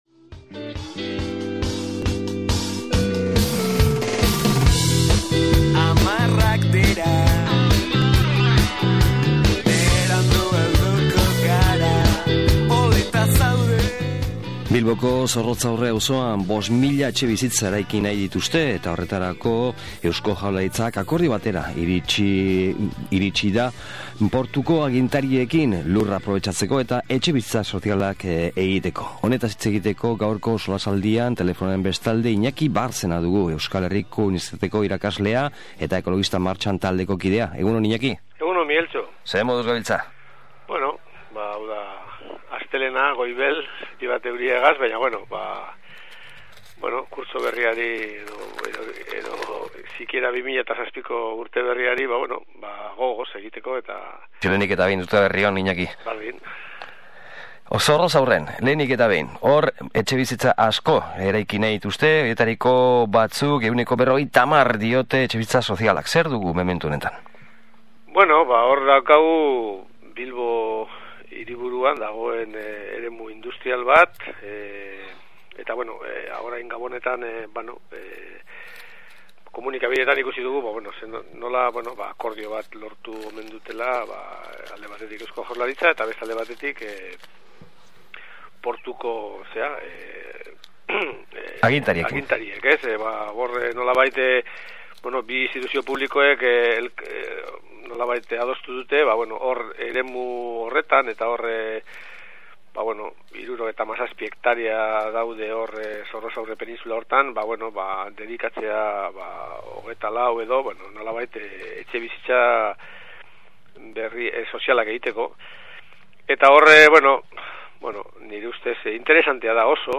SOLASALDIA: Etxebizitzak | Bilbo Hiria irratia